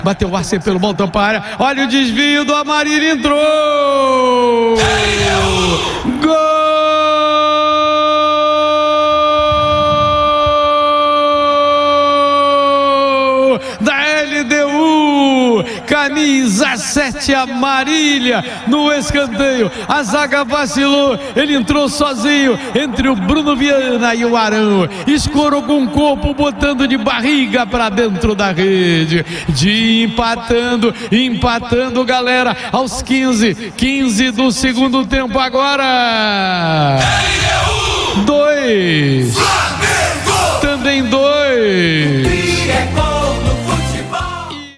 Ouça os gols da vitória do Flamengo sobre a LDU com a narração de José Carlos Araújo